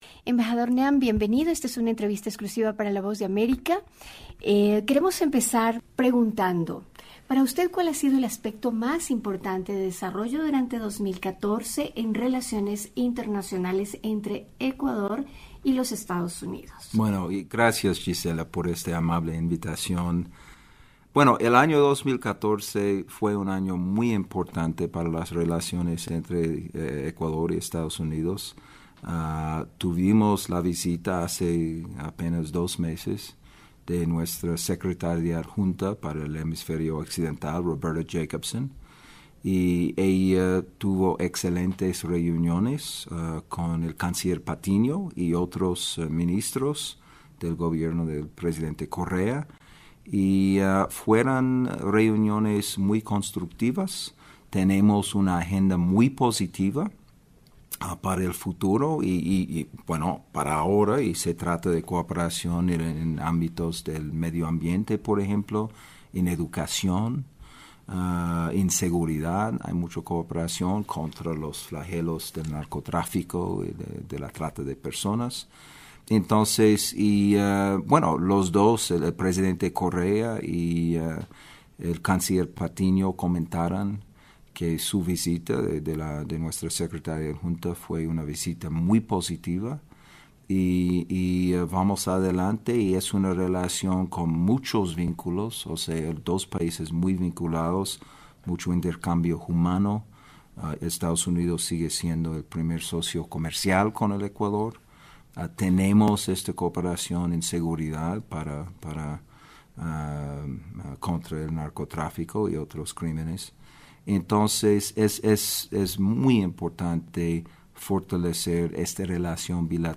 Entrevista: Embajador de EE.UU. en Ecuador, Adam Namm